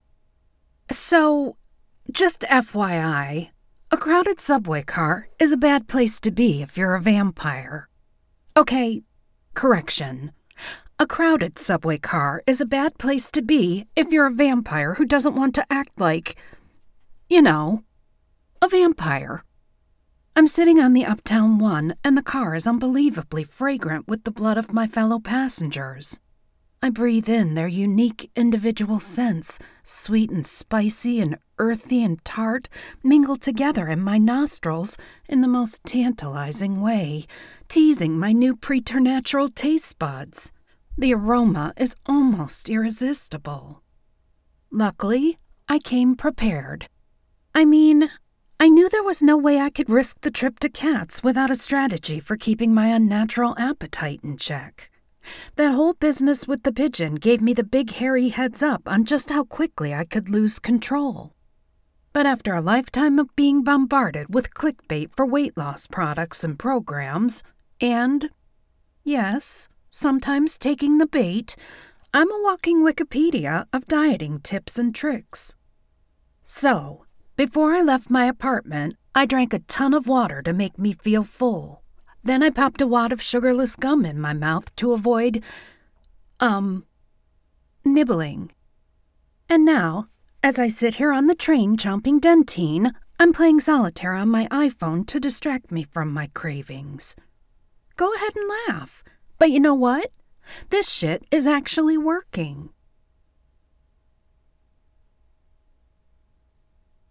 Narrator
Accent Capabilities: Full narrative: American, Midwest, and Southern
Secondary characters: English/British, Canadian, Russian, Middle Eastern, Hispanic, Asian and Irish.